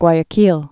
(gwīə-kēl)